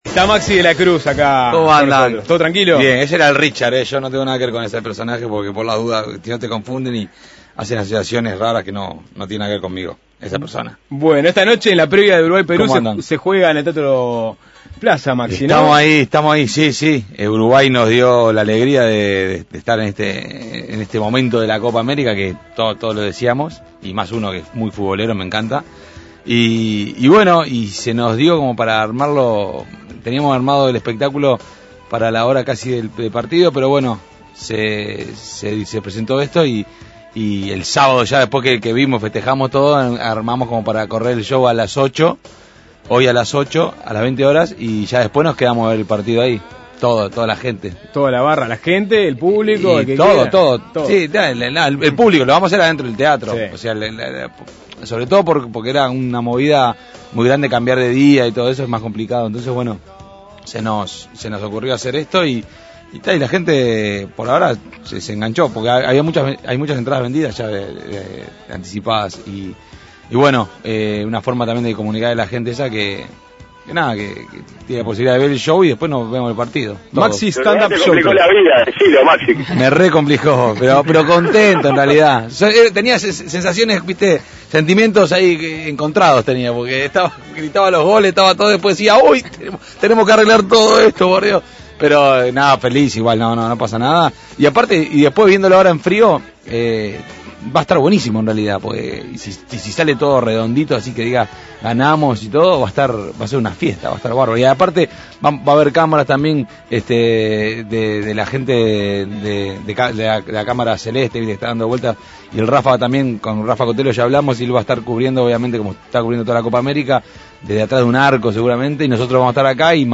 Suena Tremendo dialogó con el artista para conocer detalles de su espectáculo.